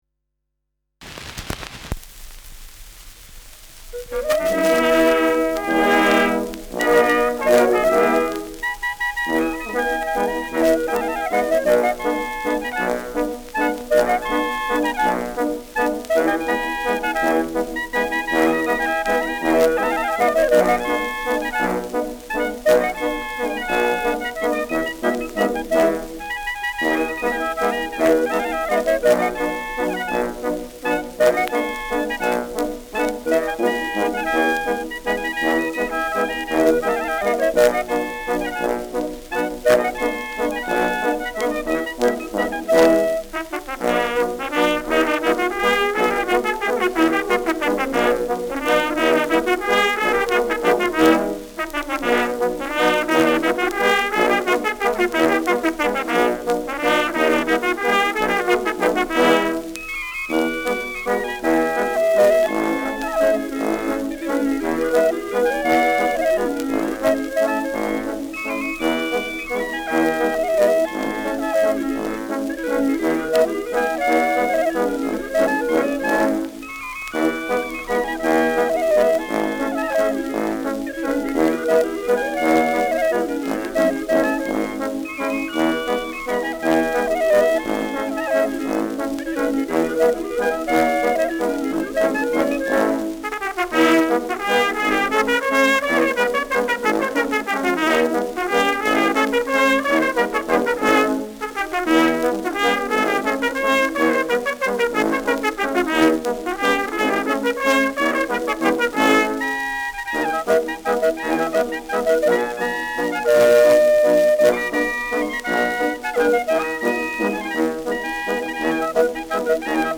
Schellackplatte
Stärkeres Grundrauschen : Gelegentlich leichtes bis stärkeres Knacken : Verzerrt an lauteren Stellen